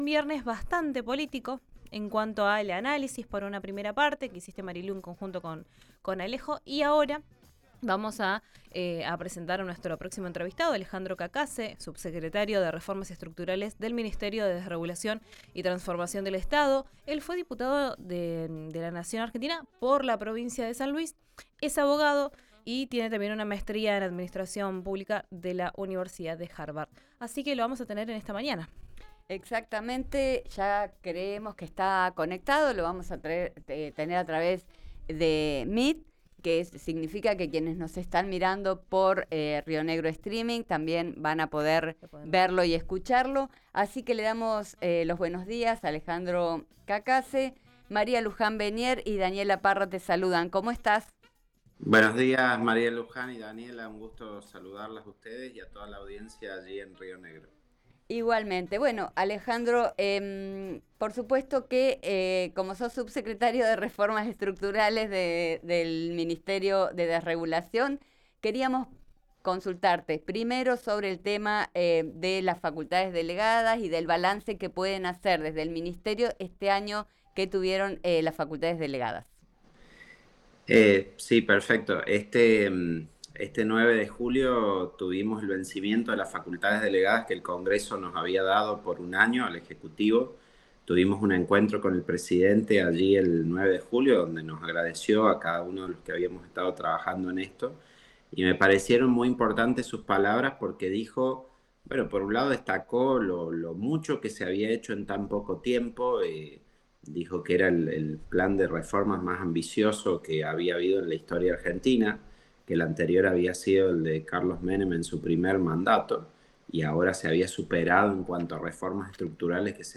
Alejandro Cacace, subsecretario de Reformas Estructurales del ministerio de Desregulación, habló en RÍO NEGRO RADIO tras la finalización de las facultades delegadas.
Así se desprende de la extensa entrevista que dio el subsecretario de Reformas Estructurales del ministerio de Desregulación, Alejandro Cacace, a RÍO NEGRO RADIO en la que habló sobre el día después del fin de las facultades delegadas y puso el foco en los gobernadores.